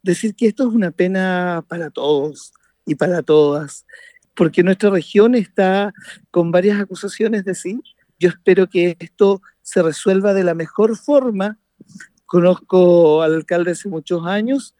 Un caso que se calificó como “una pena” para todos por parte del concejal socialista, Esteban Barriga. Quien recordó que otros alcaldes de la región fueron condenados por delitos sexuales y otros enfrentan procesos ante la justicia.